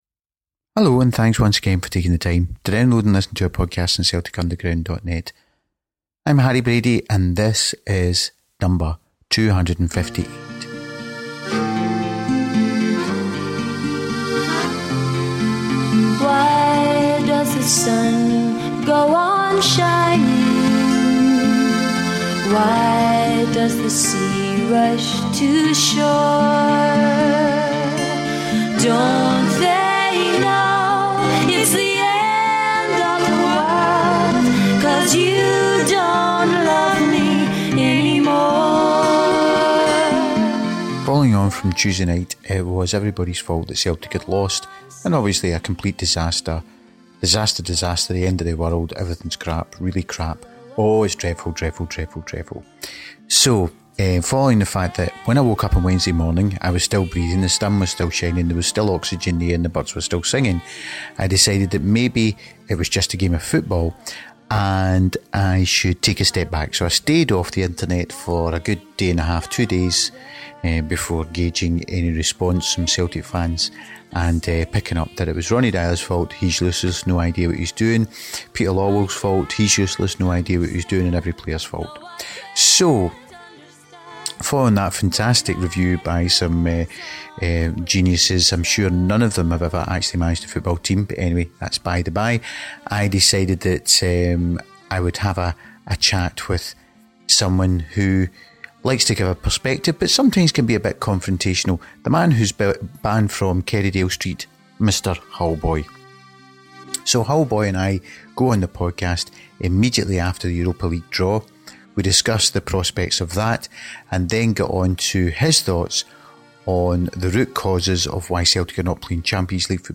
with his calming Hull tones to provide his overview.